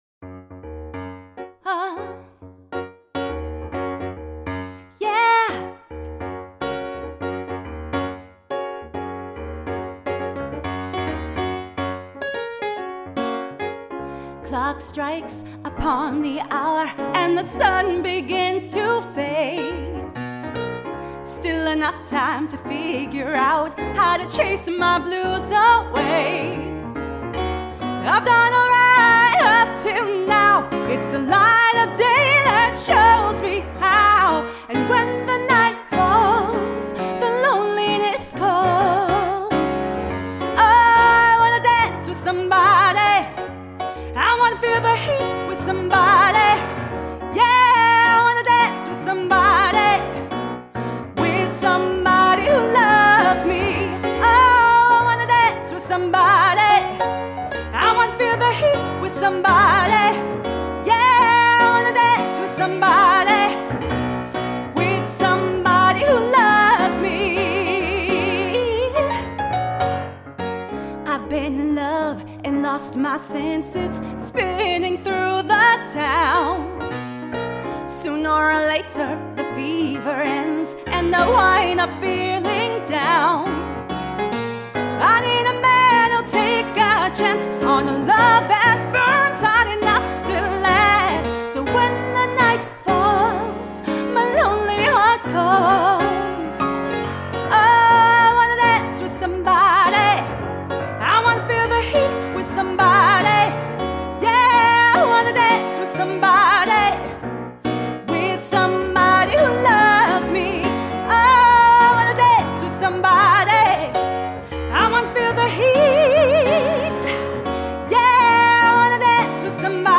Keys